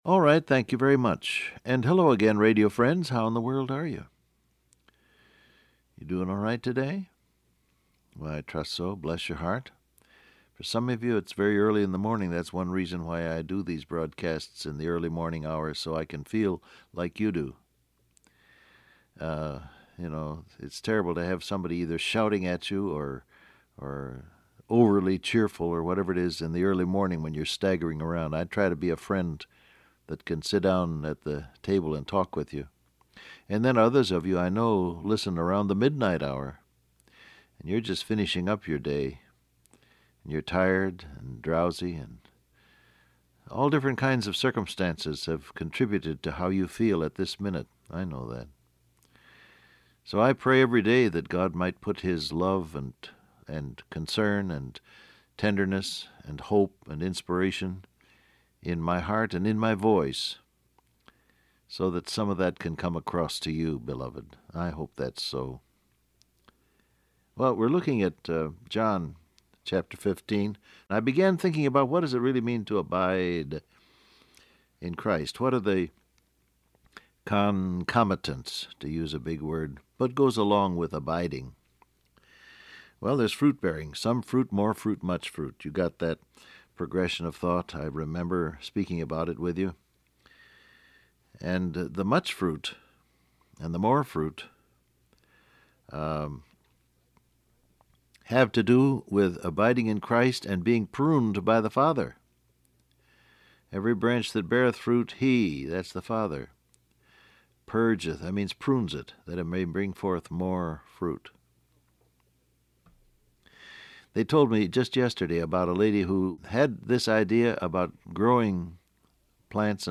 Download Audio Print Broadcast #6927 Scripture: John 15:9-11 , John 3:16 Topics: Abide In Christ , Bear Fruit , Agape Love , Loved Transcript Facebook Twitter WhatsApp Alright, thank you very much.